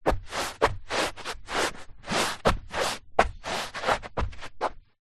Звук шаркающих ног по ковру